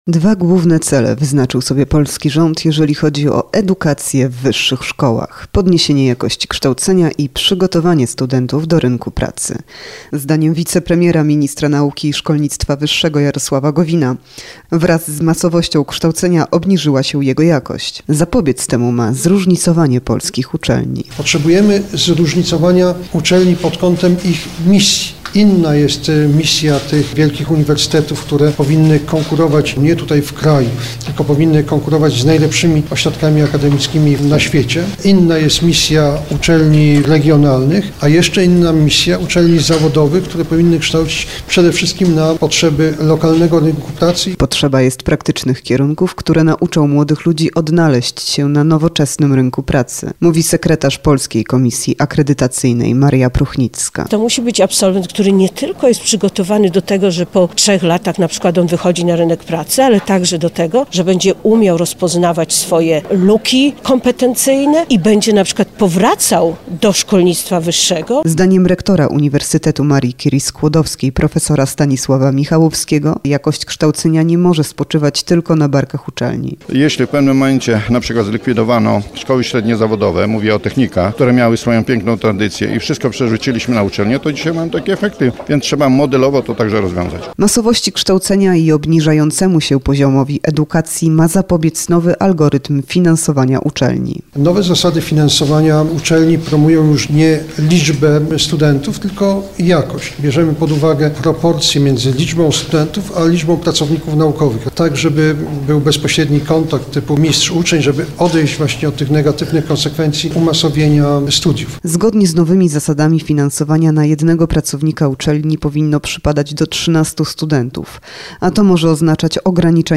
O wyzwaniach, nowych zasadach finansowania i problemach szkolnictwa wyższego rozmawiali uczestnicy spotkania programowego Narodowego Kongresu Nauki, które odbyło się w Lublinie.
w_lublinie_odbylo_sie_spotkanie_programowe_narodowego_kongresu_nauki.mp3